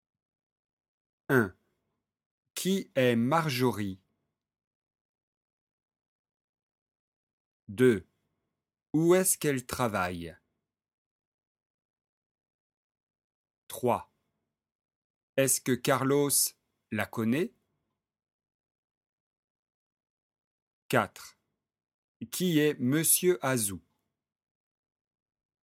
🔷 Dialogue